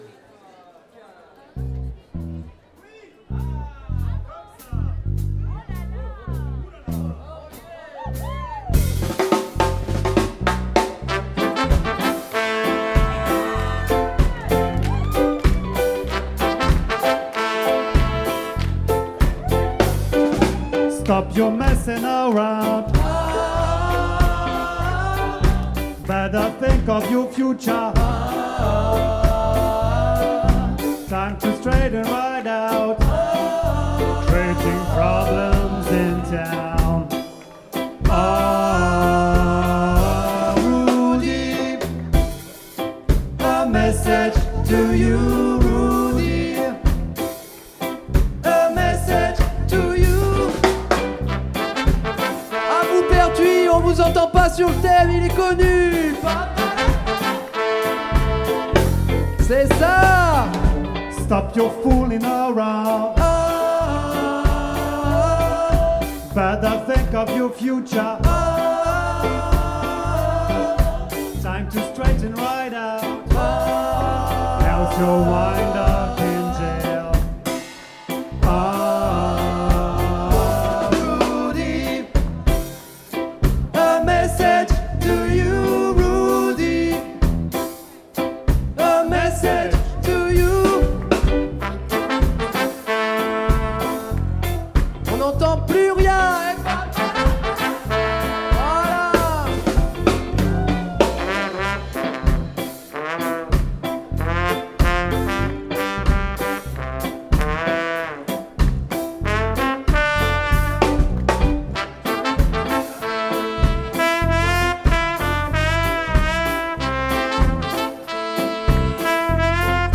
guitare
batterie
trompette
chant et clavier